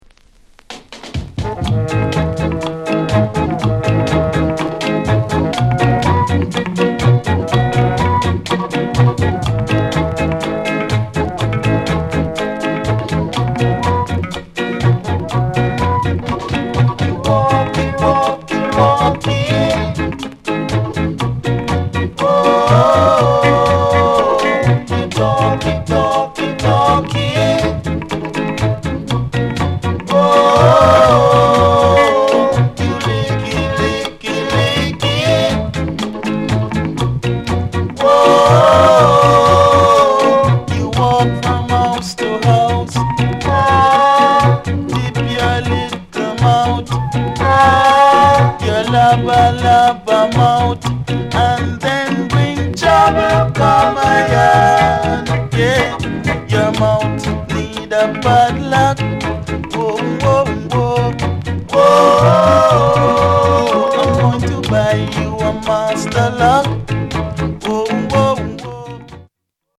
FUNKY INST